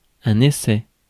Ääntäminen
IPA : /stæb/ US : IPA : [stæb]